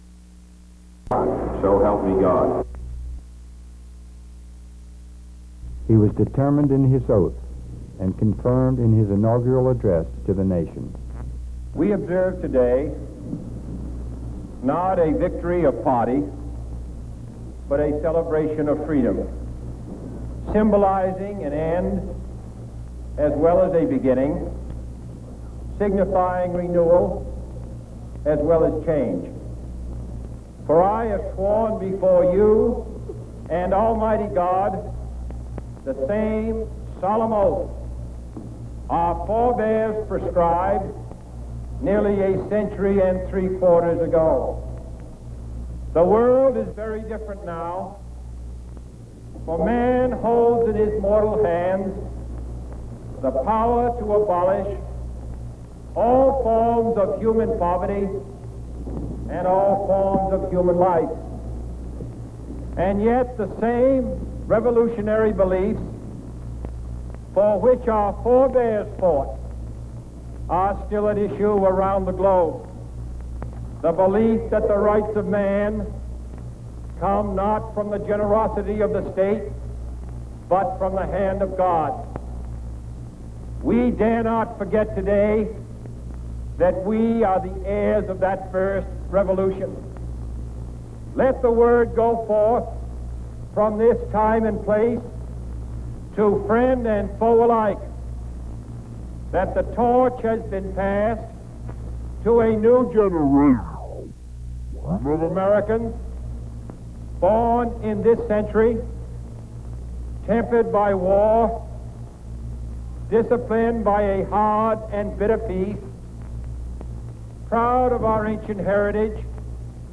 U.S. President John F. Kennedy's inaugural address